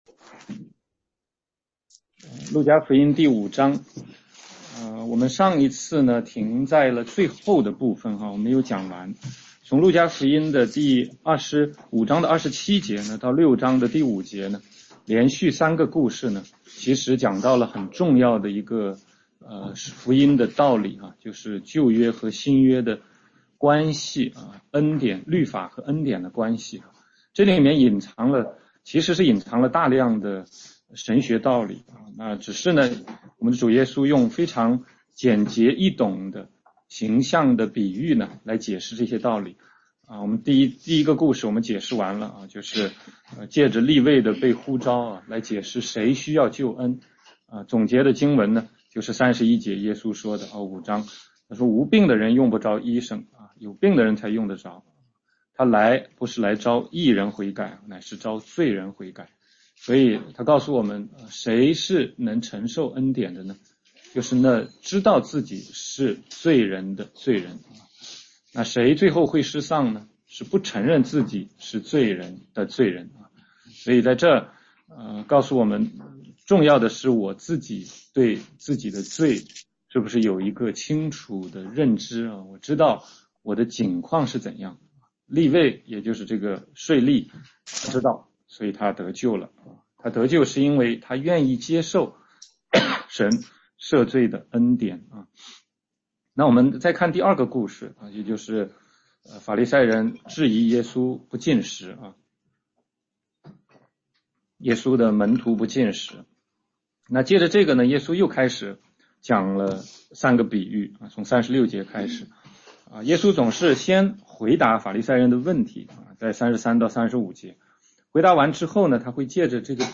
全中文查经